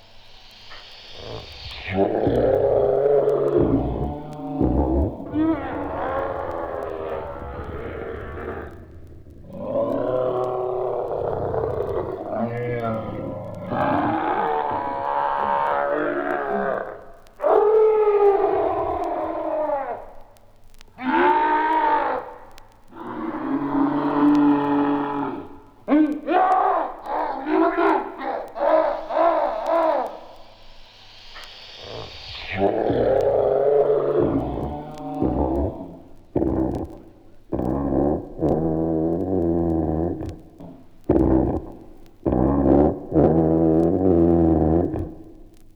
• monsters roaring - vinyl sample.wav
monsters_roaring_1YE.wav